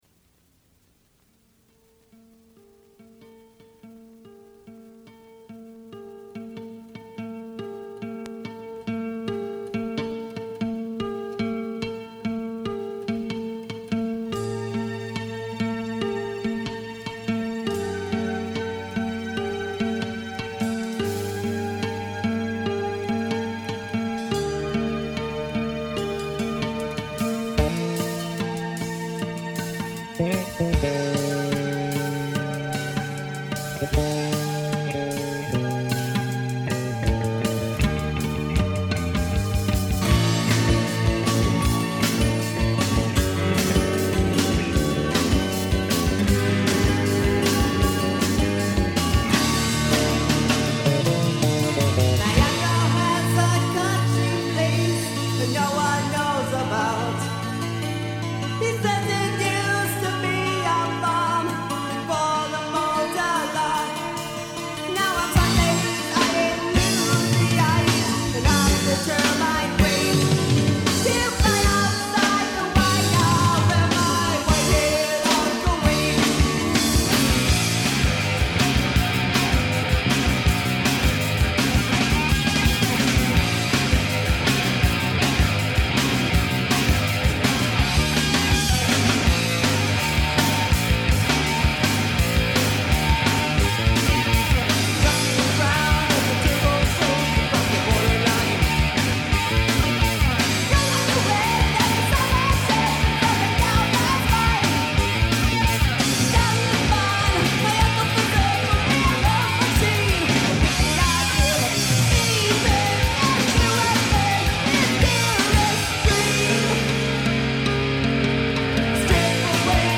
American progressive metal band